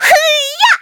Taily-Vox_Attack3.wav